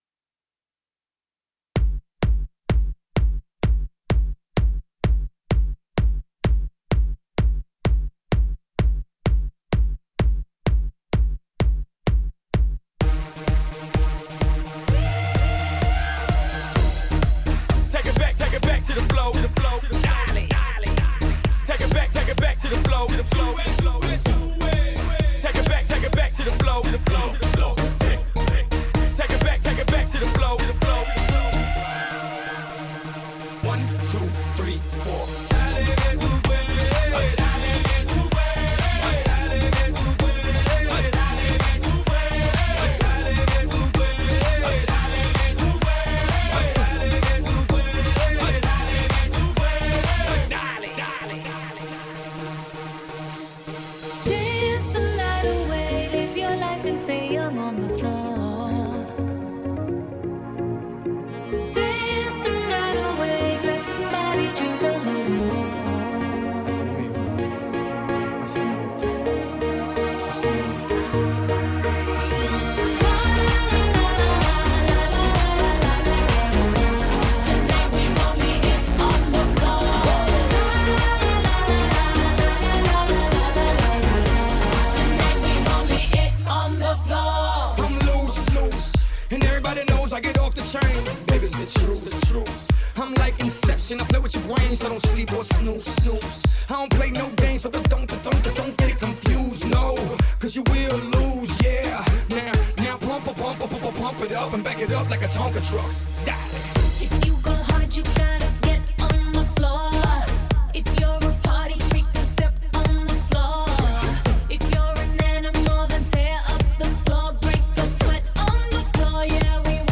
A live oldies and pop mix
The EMG Podcast Mix: Latin & Pop Mix